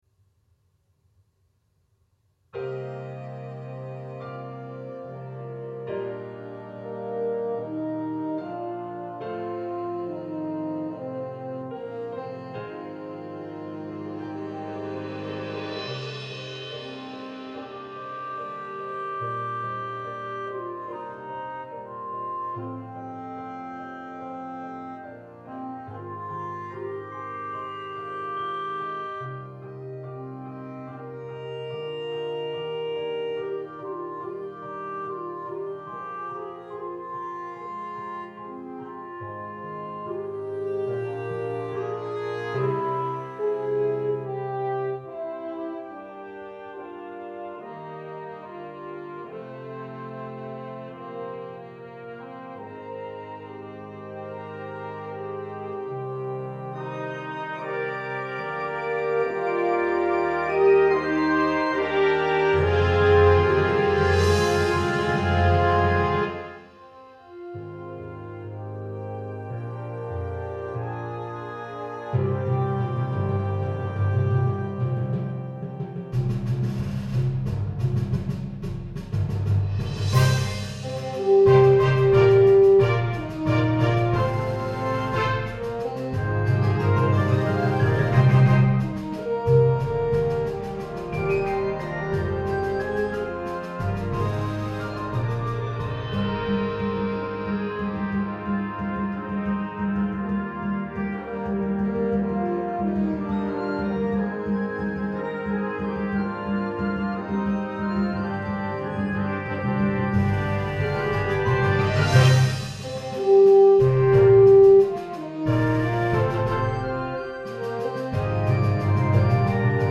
Genre: Band
Mallet Percussion (bells, xylophone, chimes, vibraphone)